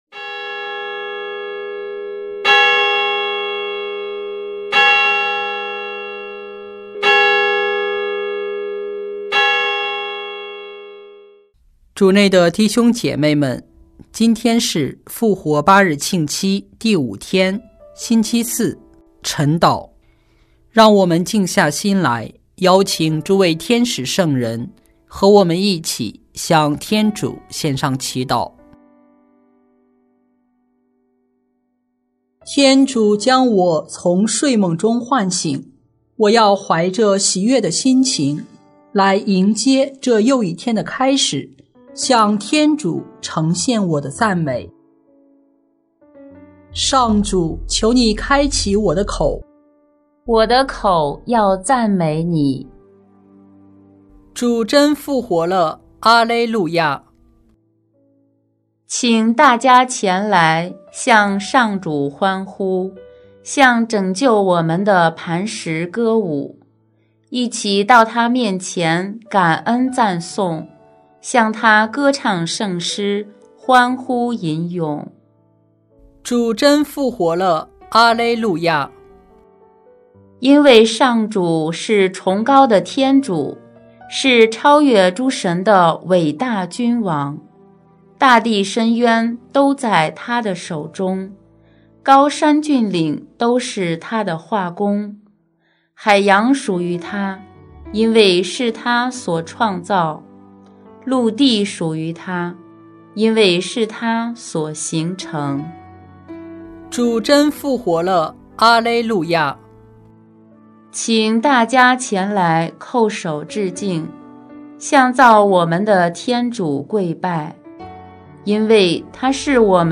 【每日礼赞】|4月24日复活八日庆期星期四晨祷